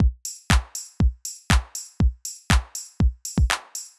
ITA Beat - Mix 3.wav